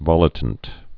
(vŏlĭ-tnt)